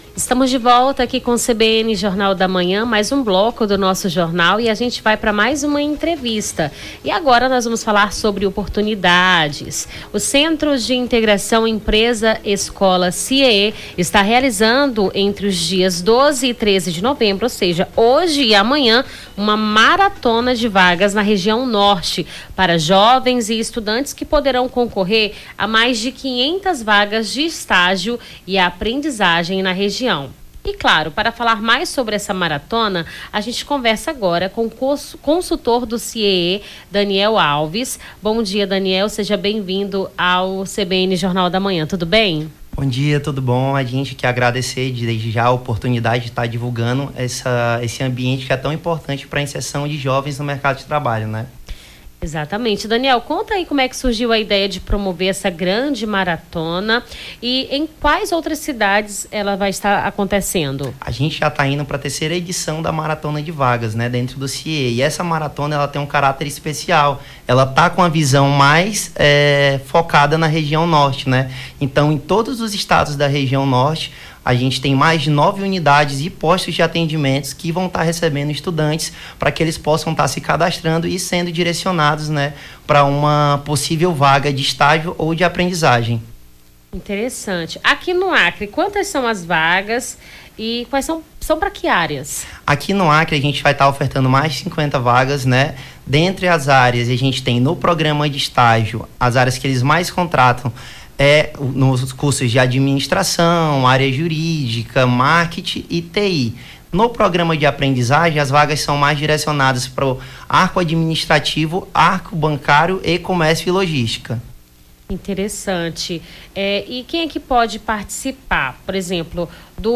Nome do Artista - CENSURA- ENTREVISTA CIEE MARATONA DE VAGAS (12-11-24).mp3